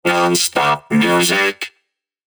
• Eenstemmige Robo-Voice-jingles op 1 toonhoogte
Zonder Soundeffects